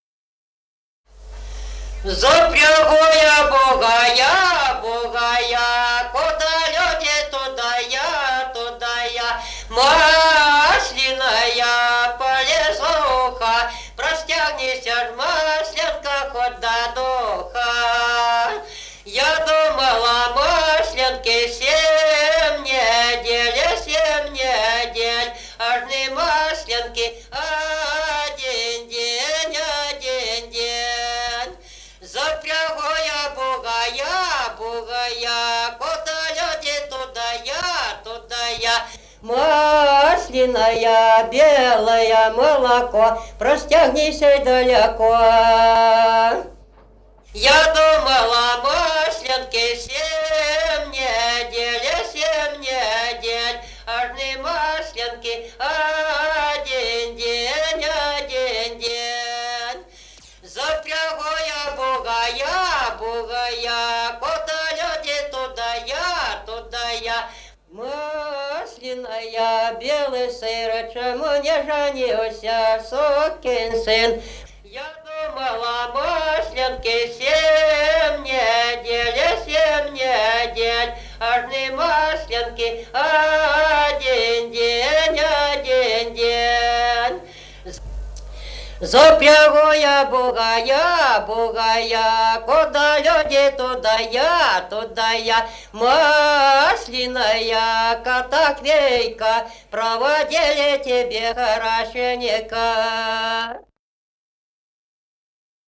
Народные песни Стародубского района «Запрягу я бугая», масленичная.
1953 г., д. Камень.